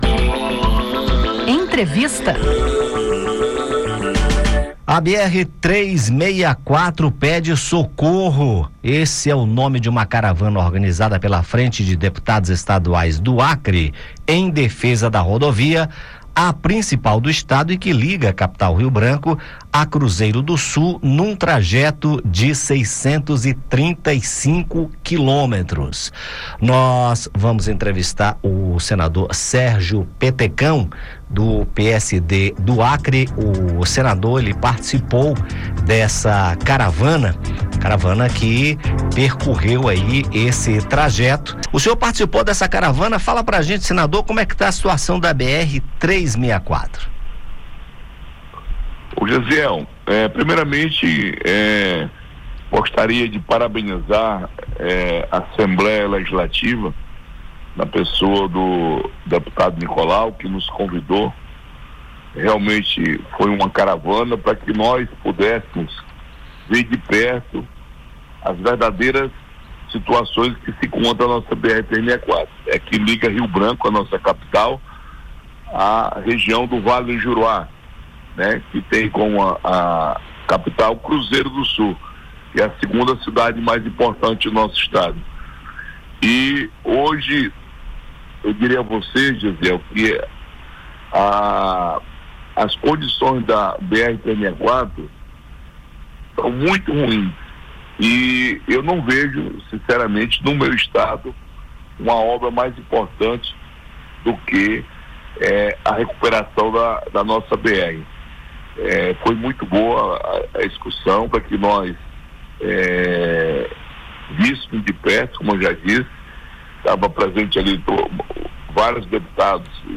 O senador Sérgio Petecão (PSD-AC) participou de uma caravana organizada pela frente de deputados estaduais do Acre em defesa da principal rodovia do estado, a BR-364, que liga a capital Rio Branco a Cruzeiro do Sul, num trajeto de 635 quilômetros. Em entrevista, Petecão fala sobre a situação da BR-364 e os prejuízos que os problemas na rodovia causam para o estado e destaca as ações do Ministério dos Transportes e do Departamento Nacional de Infraestrutura de Transportes (DNIT) sobre as obras de reconstrução.